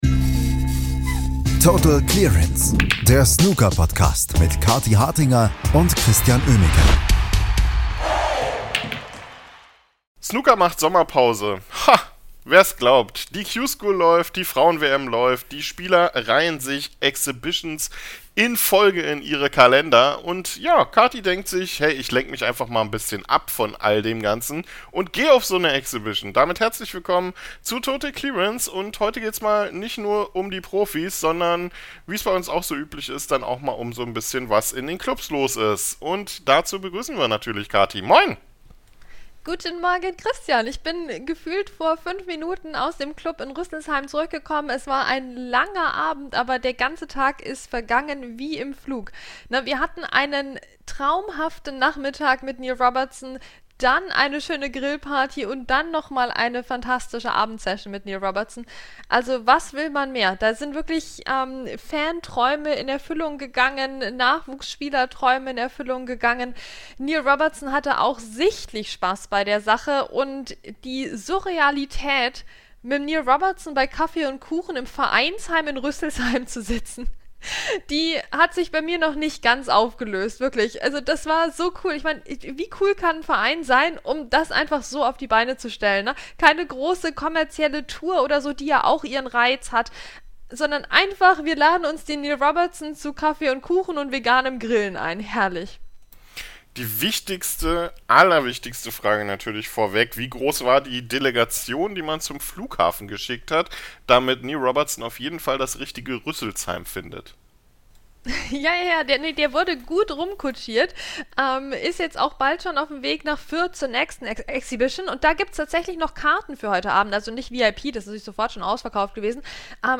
Außerdem läuft in Leicester weiter die Q-School, die Asien-Variante ist mit zwei weiteren Tourkarten beendet und in China läuft die Frauen-WM auf ihr Finale zu. Doch die besten Eindrücke erhaltet ihr in dieser Folge aus Rüsselsheim mit einem glänzend aufgelegten Neil Robertson, der Fans und Spieler gleichermaßen beeindruckte.